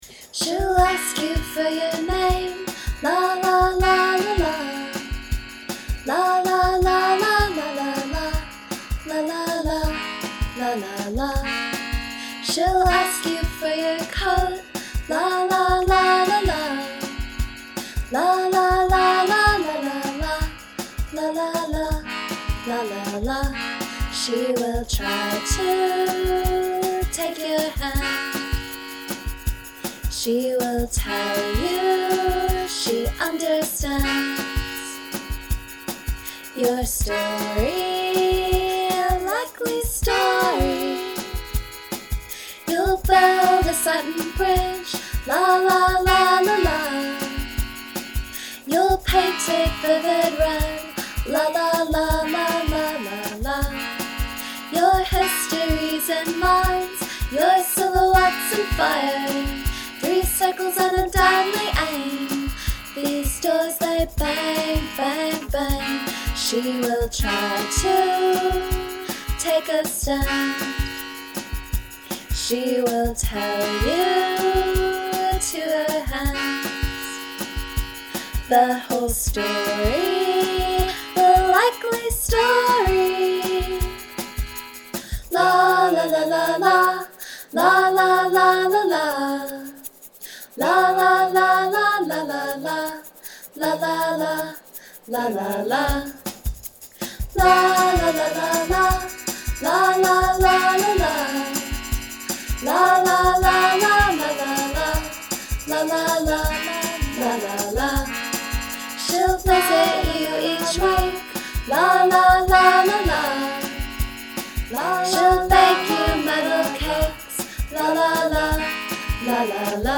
was trying to write something with less words. sort of limited on instrumentation around here these days. i'm going to re-do the melodica part to be less boring/off-tempo. this is just sort of a scratch version for the sake of having something down.
i'm not sure about the structure for this. the la-la part goes on a bit (!), and the drums should be different under the chorus. and maybe there should be one less verse and one more chorus, or more of a build from the quiet part after the second chorus. i'm not sure.
Man, as usual, your harmonies are super.
La La sing-alongs are key.